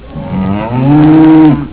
Cow 3
COW_3.wav